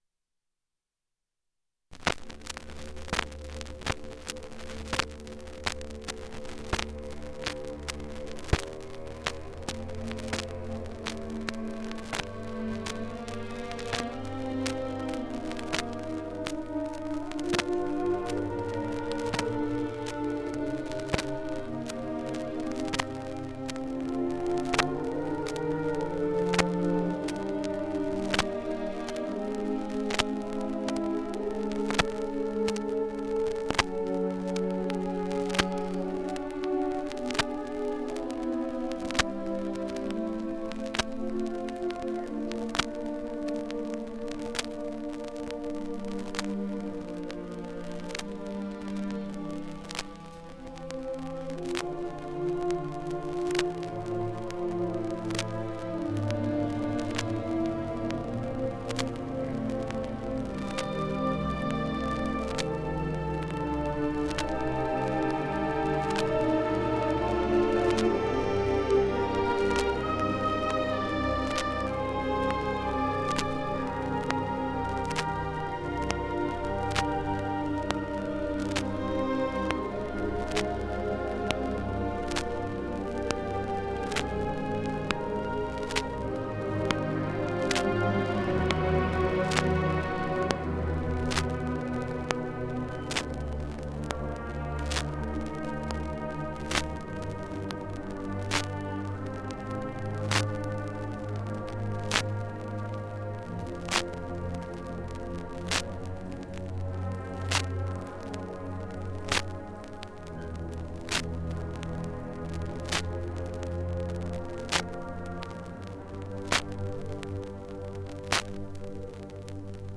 9ef2af6c795040b98485aa70ebaba75d139a8282.mp3 Title 1977 Music in May band and chorus performance recording Description An audio recording of the 1977 Music in May band and chorus performance at Pacific University.
It brings outstanding high school music students together on the university campus for several days of lessons and events, culminating in the final concert that this recording preserves.